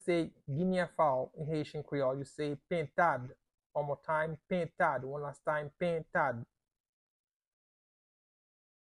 Listen to and watch “Pentad” audio pronunciation in Haitian Creole by a native Haitian  in the video below:
19.How-to-say-Guinea-Fowl-in-Haitian-Creole-–-Pentad-pronunciation-by-a-Haitian-1-1.mp3